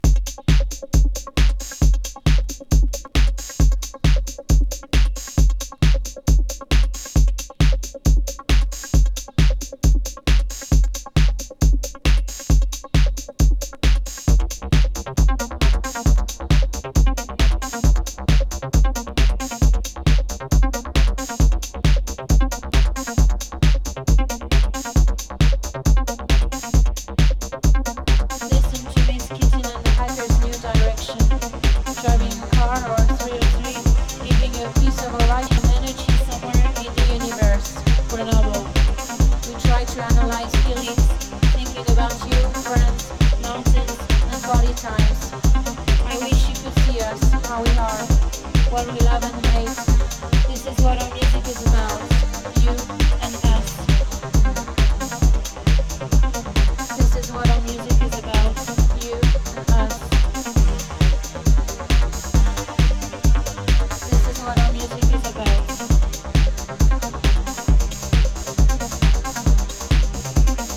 80年代のニューウェイヴ～シンセポップをテクノ～ハウスに落とし込んだフレンチ・エレクトロ名盤！
【TECHNO】【NU DISCO】